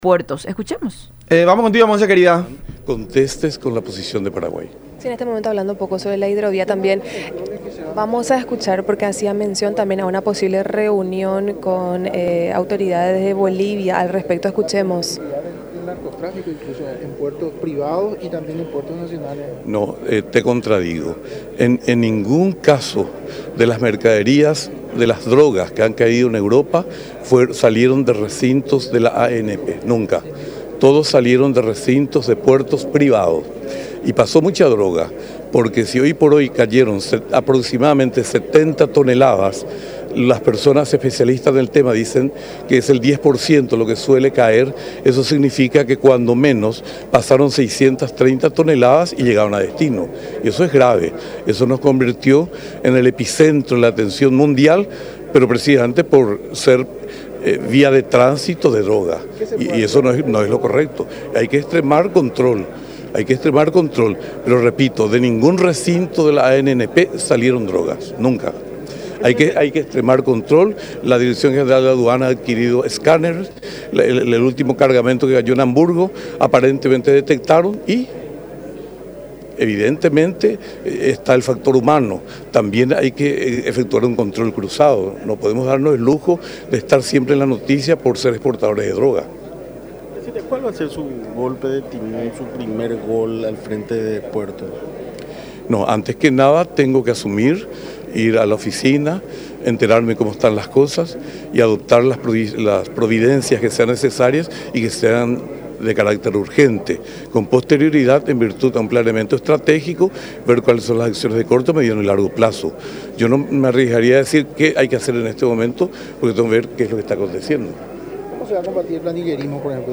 en el programa “La Unión Hace La Fuerza” por Unión TV y radio La Unión.